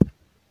beeb kick 12
Tags: 808 drum cat kick kicks hip-hop